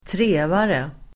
Uttal: [²tr'e:vare]